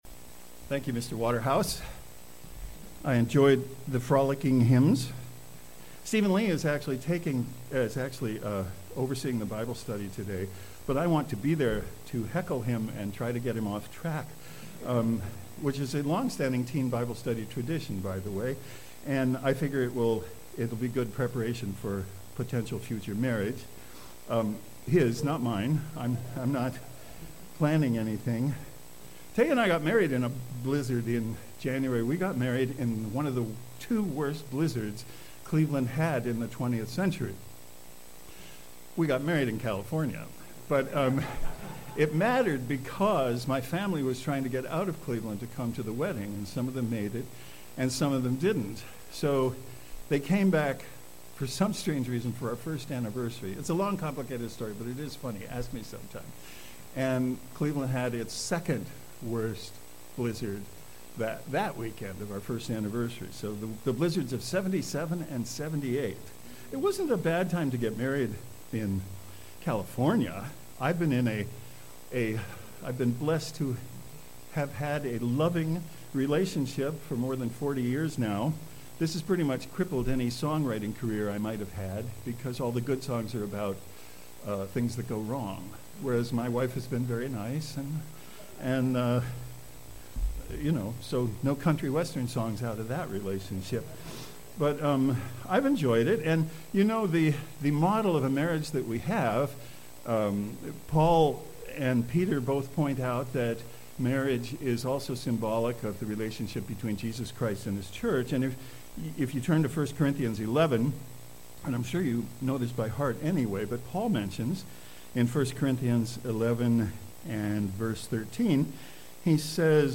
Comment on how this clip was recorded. Given in Cleveland, OH